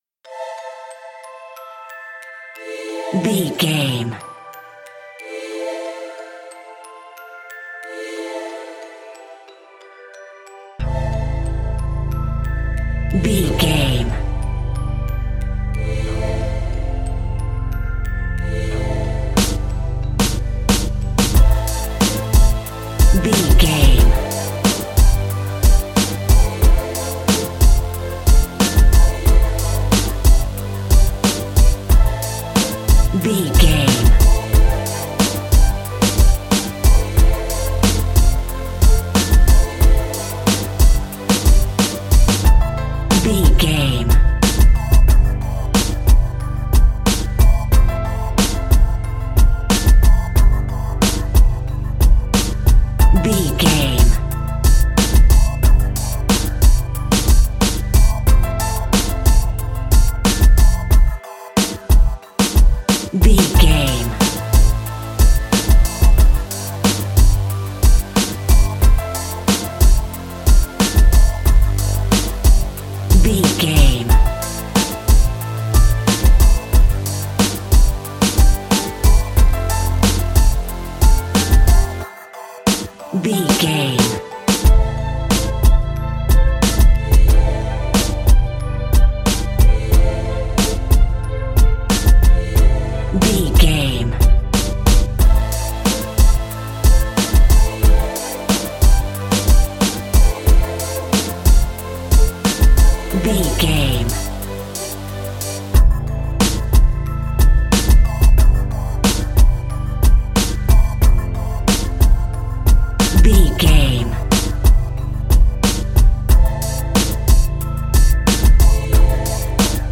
Aeolian/Minor
drum machine
synthesiser
hip hop
soul
Funk
neo soul
acid jazz
r&b
confident
energetic
cheerful
bouncy
funky
hard hitting